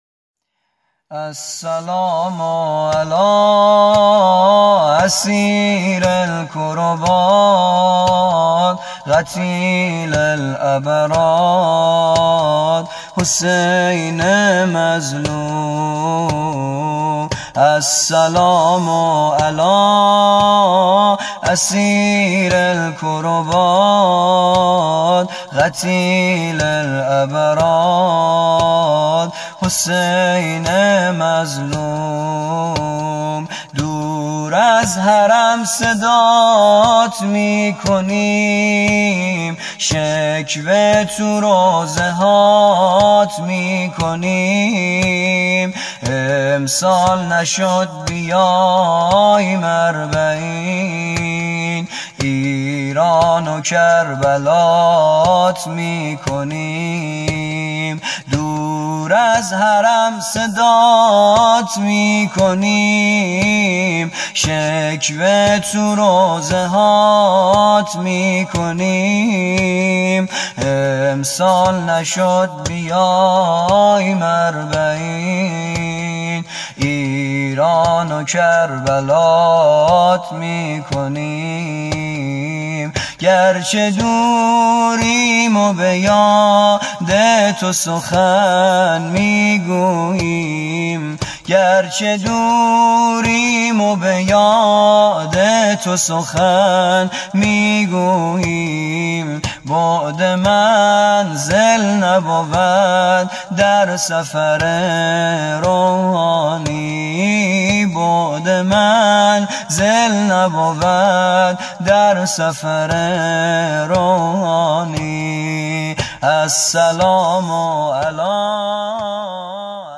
حسین الشهید اربعین زمینه ایرانو کربلات می کنیم(پیش زمینه